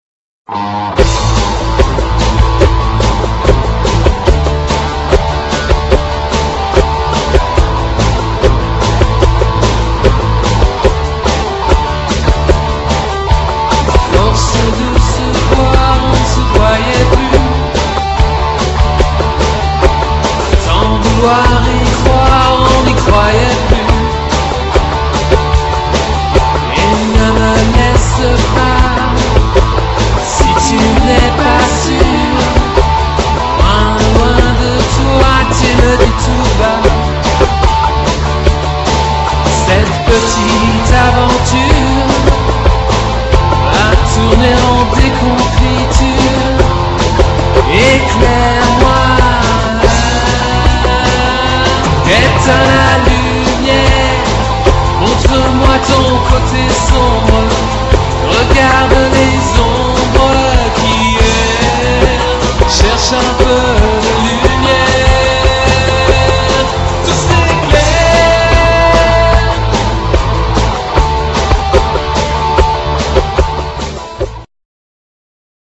reprise